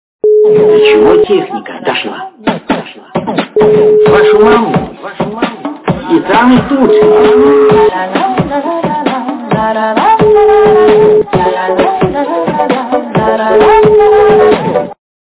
- Remix
качество понижено и присутствуют гудки.